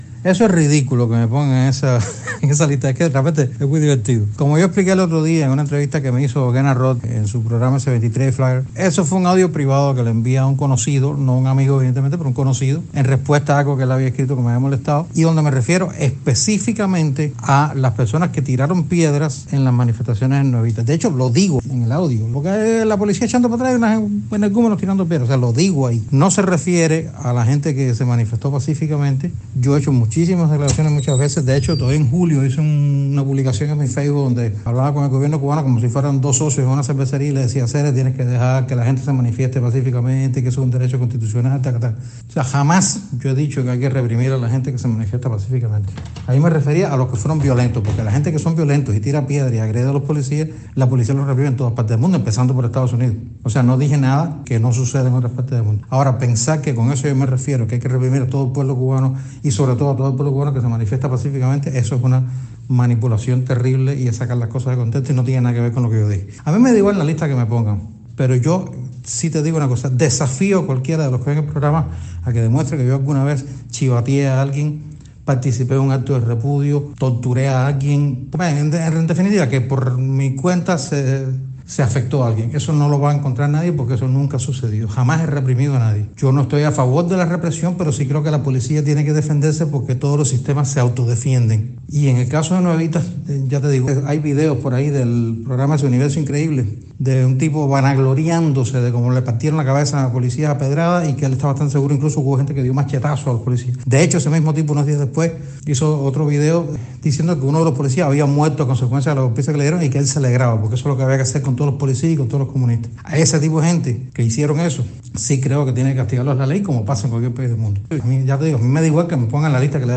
Declaraciones de Eduardo del Llano